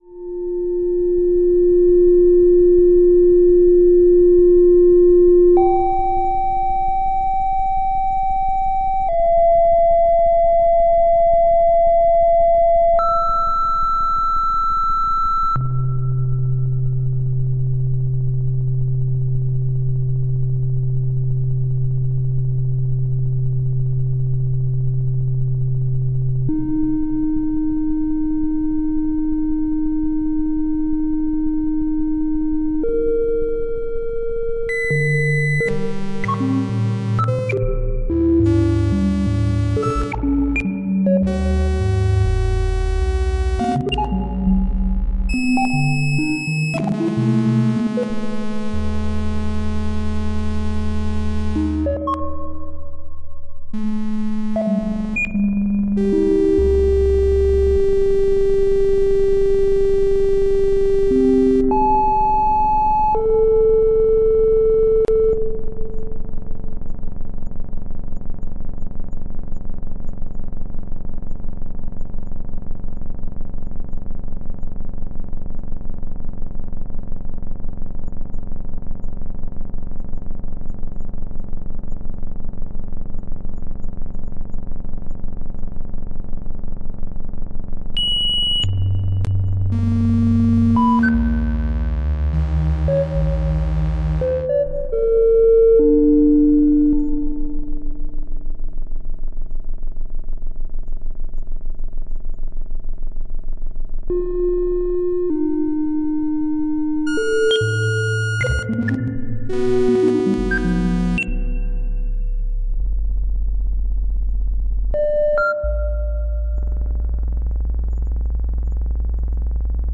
猫的声音 " themba2anew
描述：Themba的喵喵叫声被提高了，并通过电子技术增强到几乎像人类孩子一样的呜呜声
标签： 猫的声音 以电子方式改变的
声道立体声